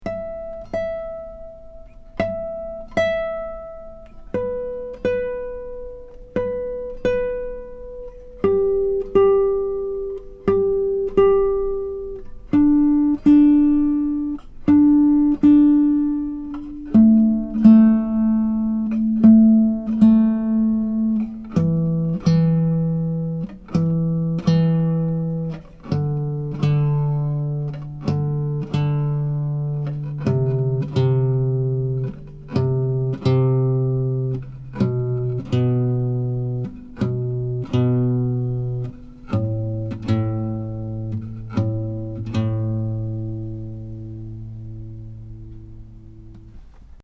The guitar has amazing sympathetic resonance and sustain, as well as good power and projection, beautiful bass responce, a very even response across the registers. These MP3 files have no compression, EQ or reverb -- just straight signal, tracked through a Wunder CM7GT multi-pattern tube mic, into a Presonus ADL 600 preamp into a Rosetta 200 A/D converter.
1 | Intonation Test (Harmonic /
fretted note @ the 12th fret)